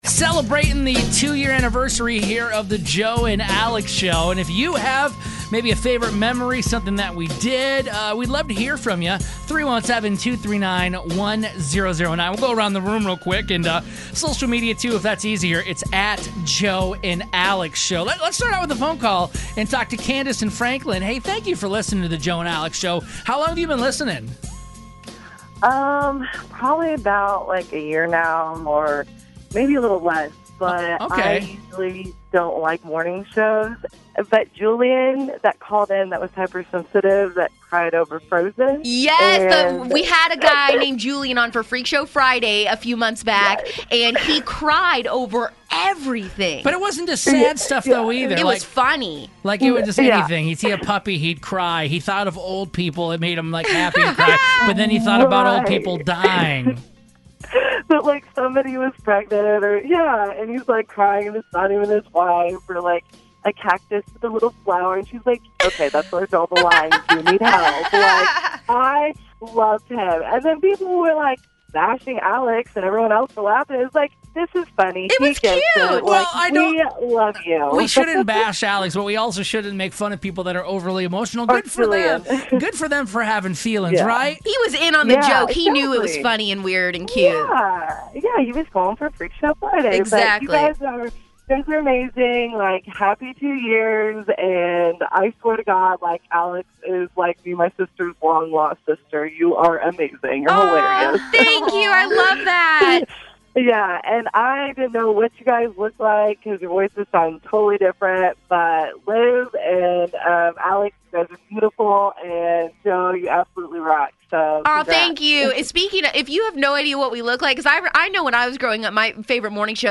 YAY! We've been on air for TWO YEARS now and we remember some of our favorite memories along with listeners calling in telling us theirs!